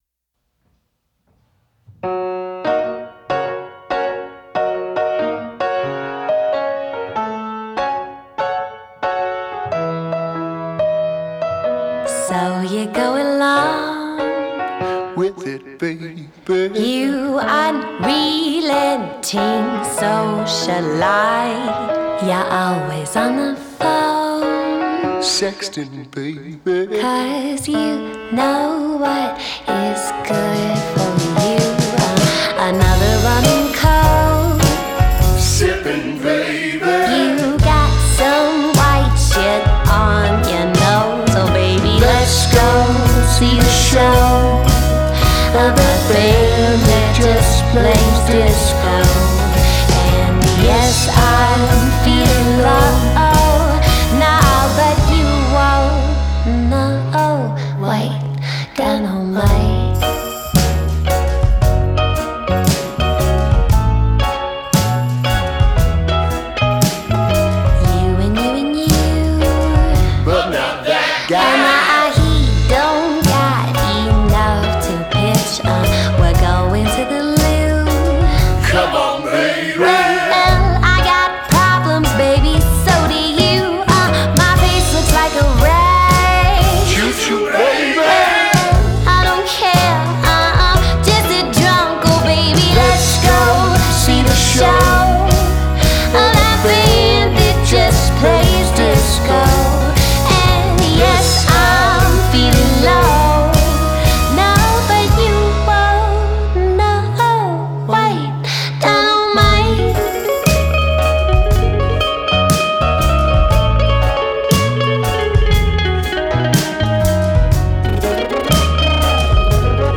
Genre: Indie Rock / Folk